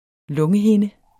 Udtale [ ˈlɔŋə- ]